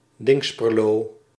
Dinxperlo (Dutch: [ˈdɪŋkspərloː]
Nl-Dinxperlo.ogg.mp3